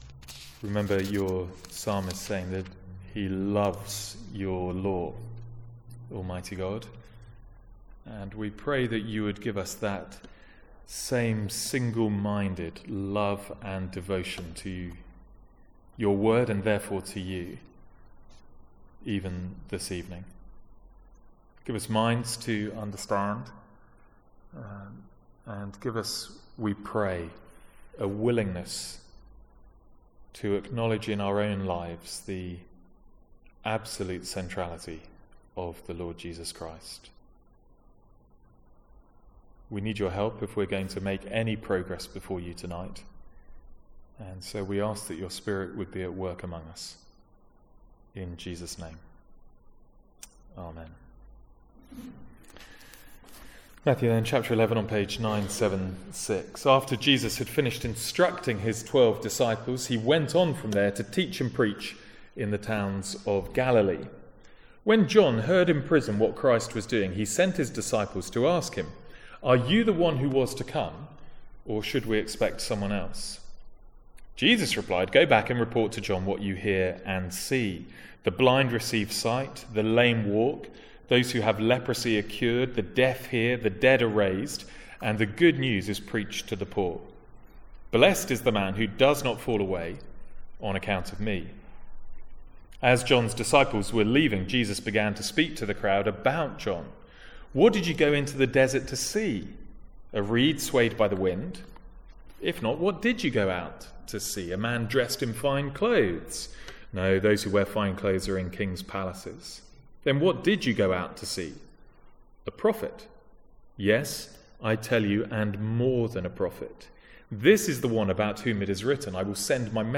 From the Sunday evening series in Matthew.